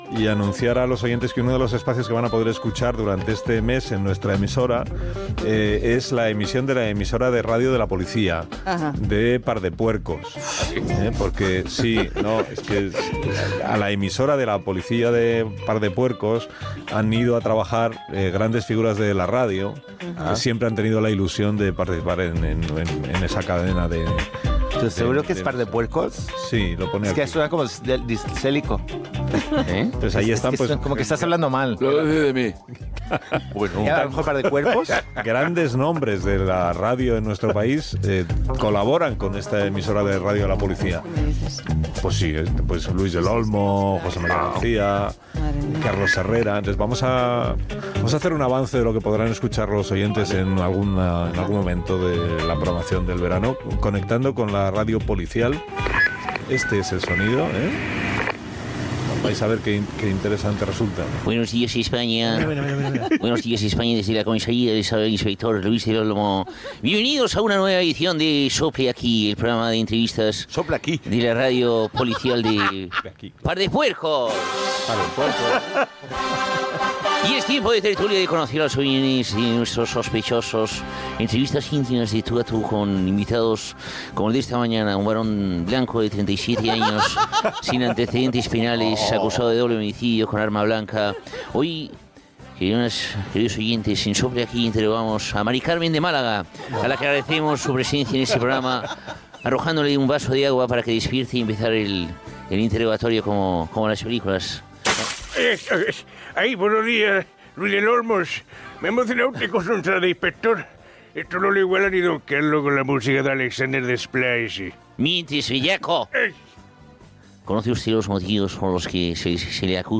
Fragment de la secció humorística "Grupo de guasa". Imitacions de Luis del Olmo, Carlos Herrera, Andrés Aberasturi, José María García i Carlos Pumares.
Info-entreteniment